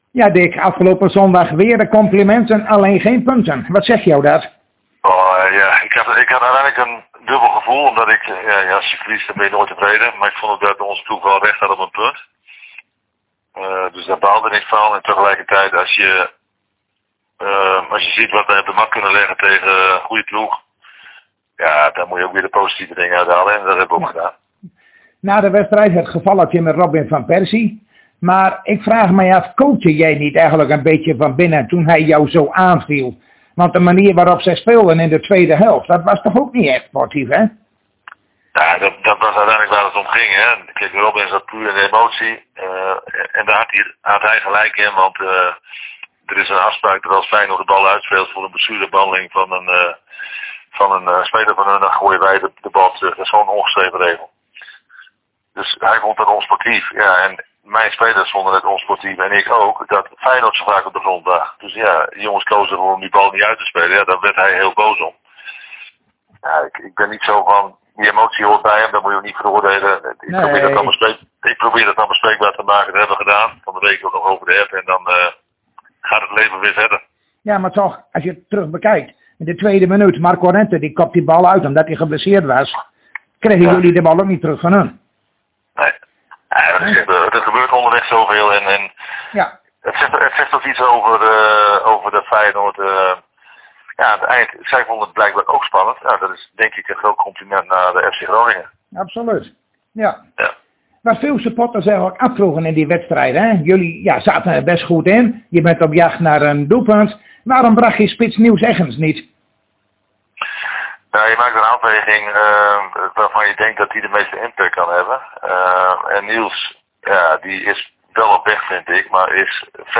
Zojuist spraken wij met trainer Dick Lukkien over de voorgaande wedstrijd tegen Feyenoord en de wedstrijden van morgenavond tegen NAC Breda.